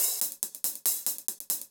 Index of /musicradar/ultimate-hihat-samples/140bpm
UHH_AcoustiHatA_140-05.wav